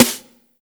84 SNARE  -L.wav